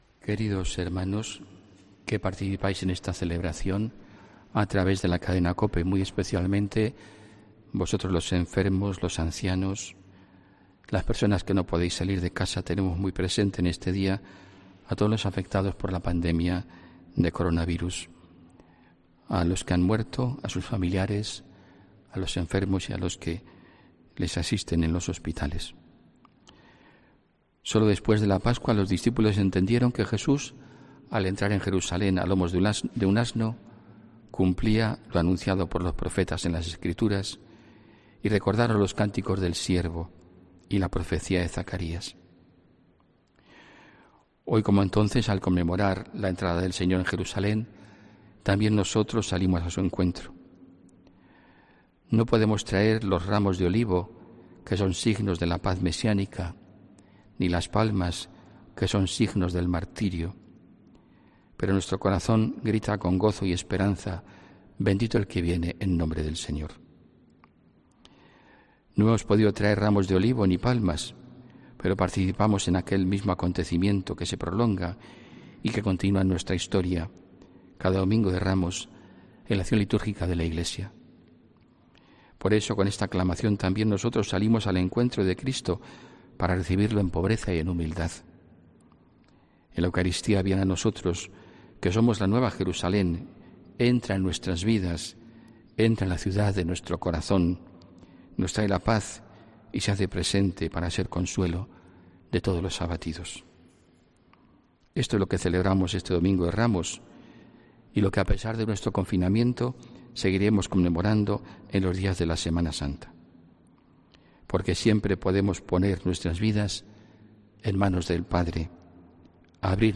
HOMILÍA 5 ABRIL 2020